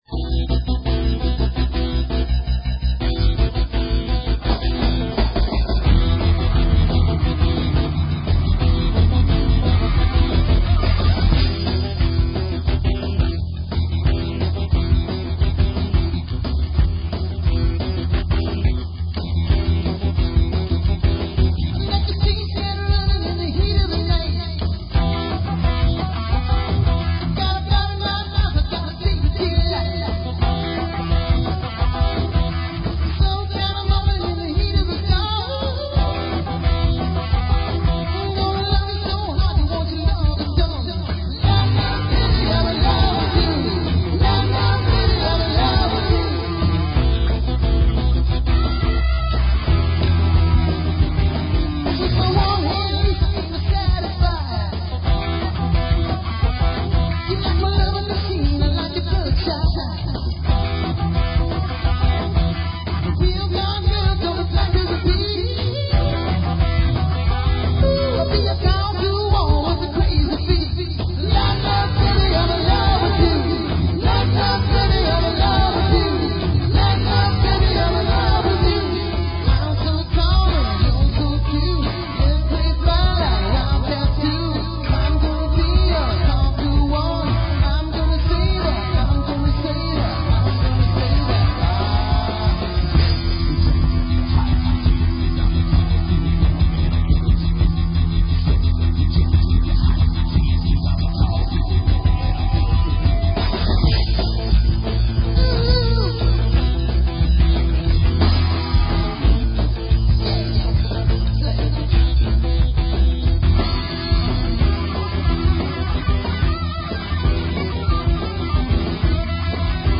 Segwaying songs together for me was always fun.
drum machine